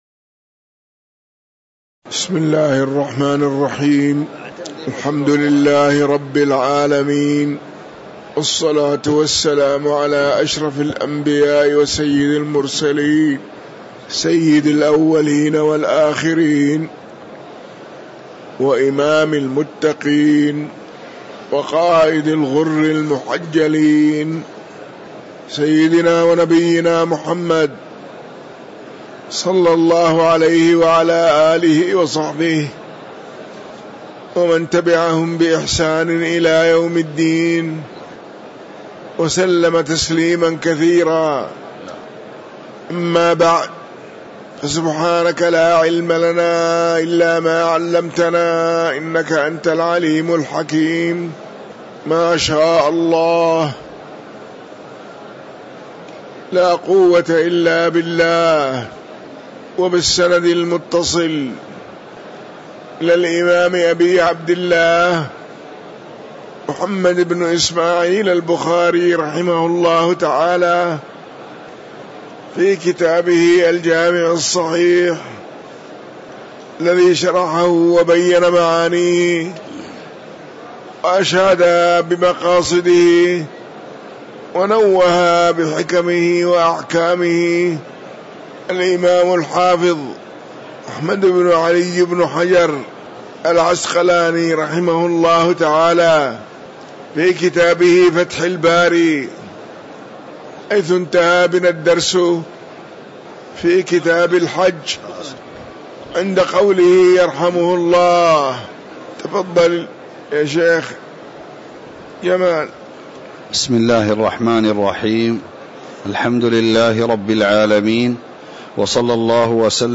تاريخ النشر ٢٣ جمادى الآخرة ١٤٤٤ هـ المكان: المسجد النبوي الشيخ